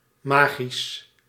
Ääntäminen
Ääntäminen France: IPA: /ma.ʒik/ Haettu sana löytyi näillä lähdekielillä: ranska Käännös Ääninäyte Adjektiivit 1. magisch 2. toverachtig Suku: f .